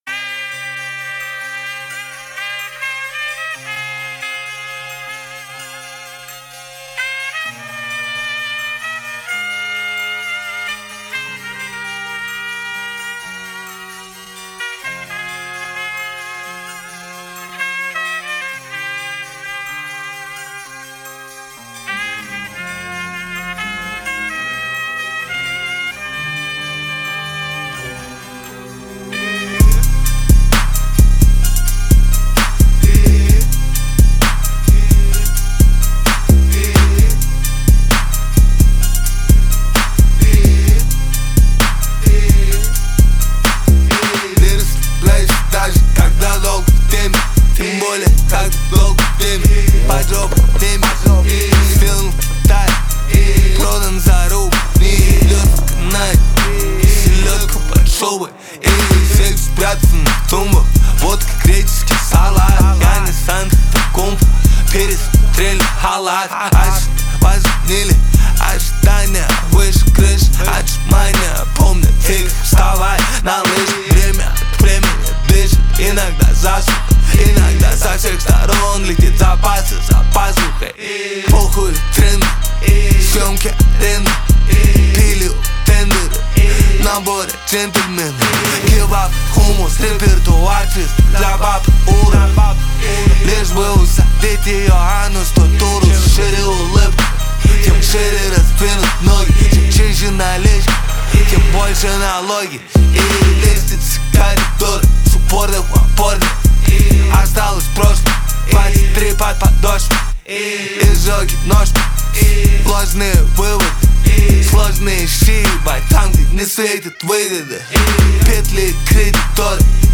Хаус музыка